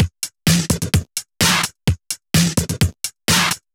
VMH1 Minimal Beats 08.wav